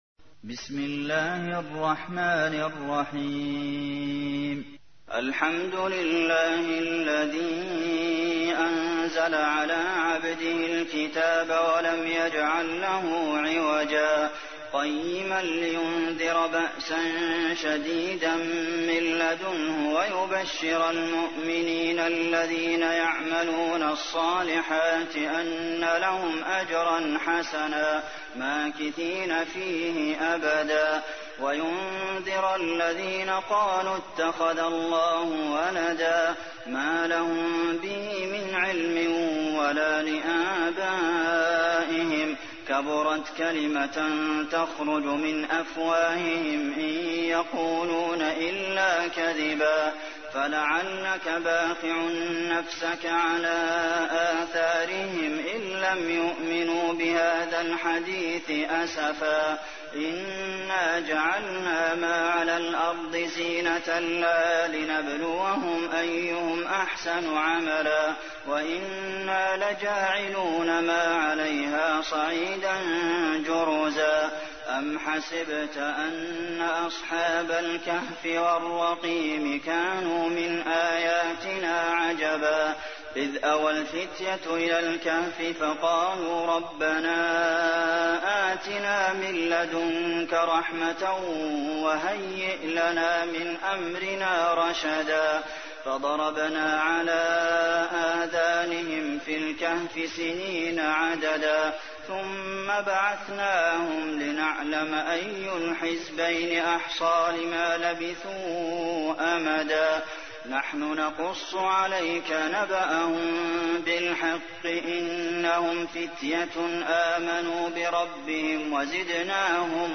تحميل : 18. سورة الكهف / القارئ عبد المحسن قاسم / القرآن الكريم / موقع يا حسين